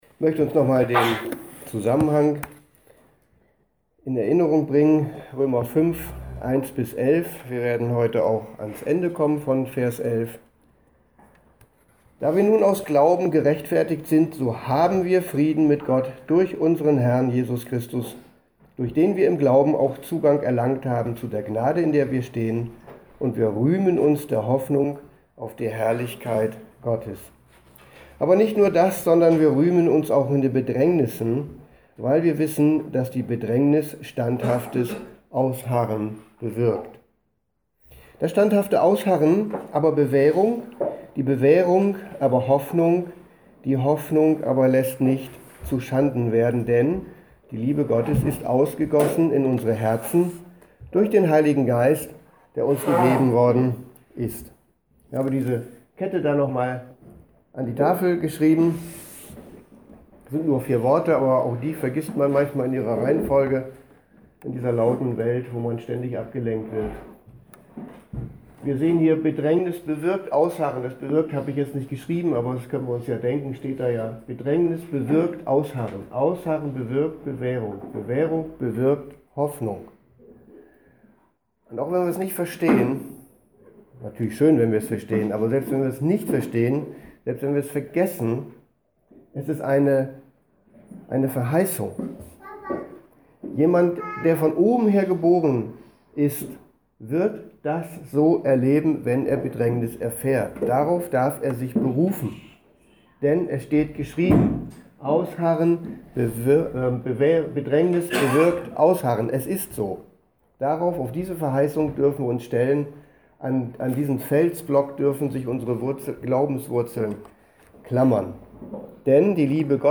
Predigt_21.06.2020_Römer_5,1-11